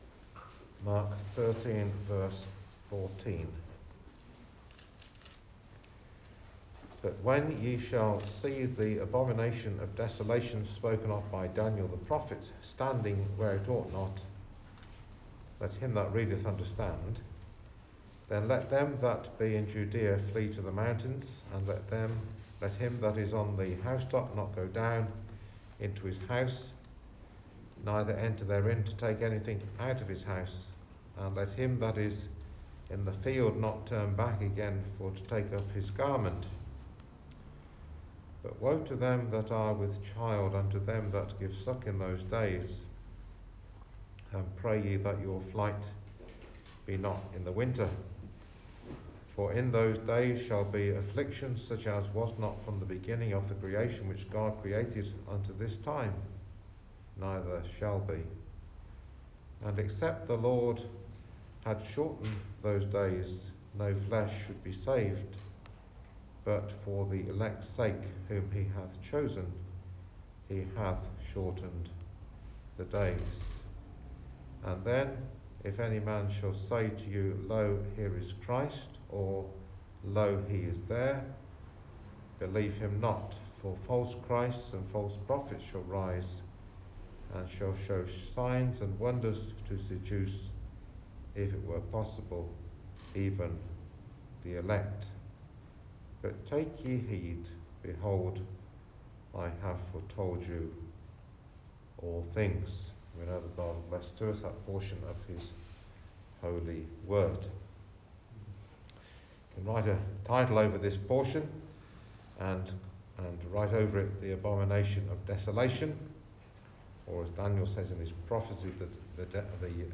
Saturday Bible Teaching – ‘Gathered together in my name’
Matthew 18:15-20 Service Type: Ministry